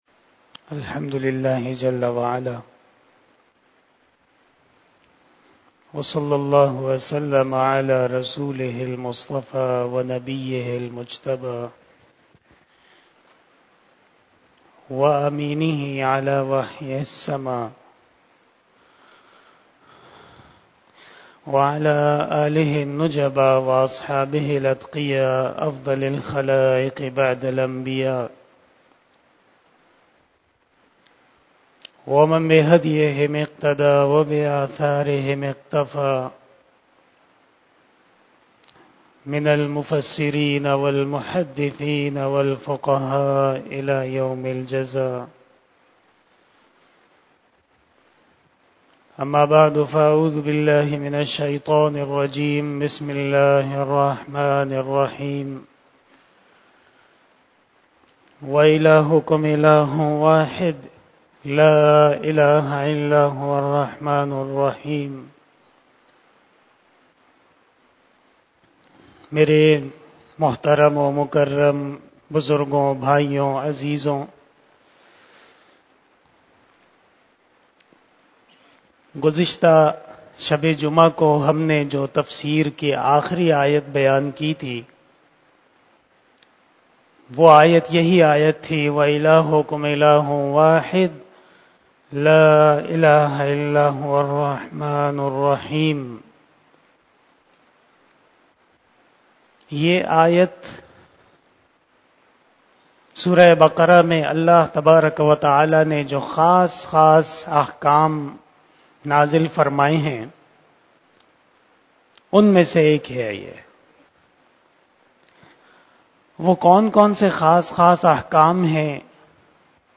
بیان شب جمعۃ المبارک27 اکتوبر2022 بمطابق یکم ربیع الثانی 1444ھ